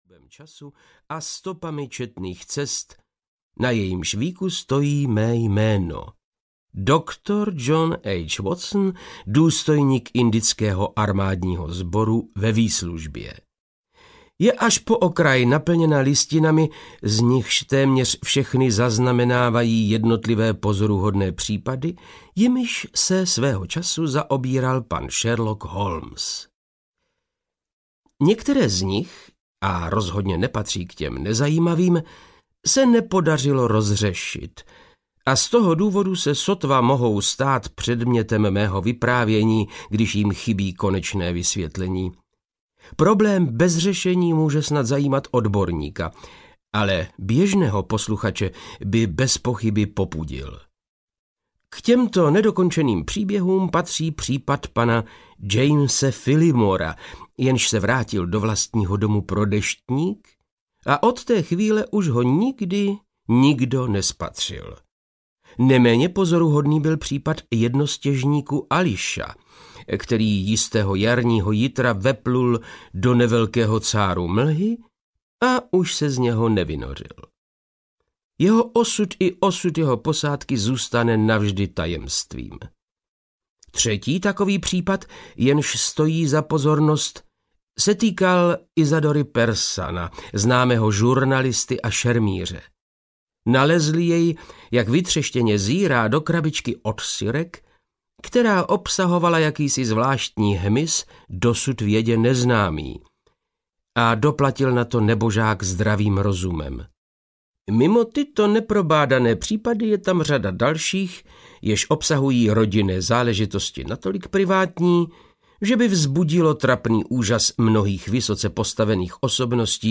Záhada na Thorském mostě audiokniha
Ukázka z knihy
• InterpretVáclav Knop